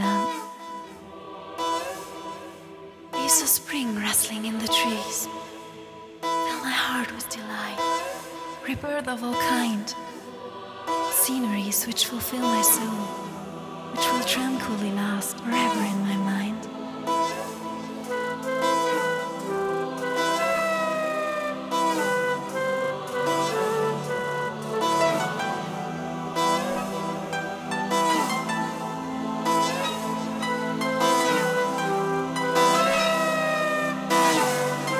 Жанр: Транс